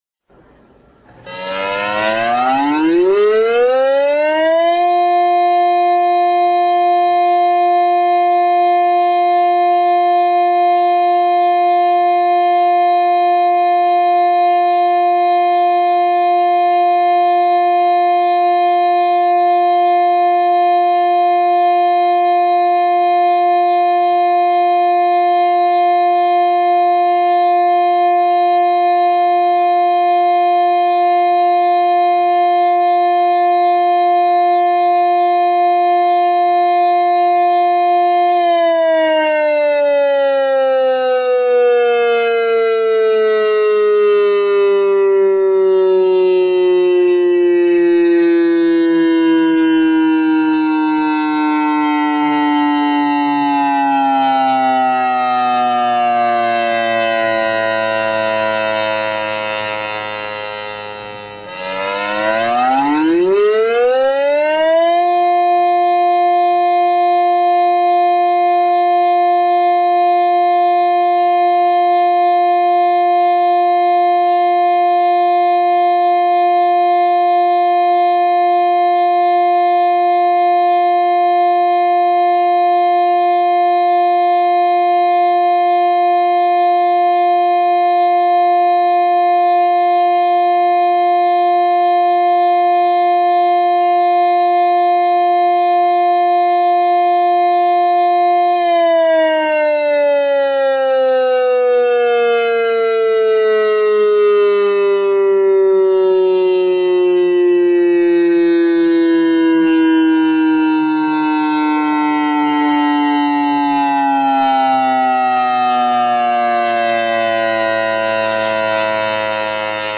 点击下载附件 预备警报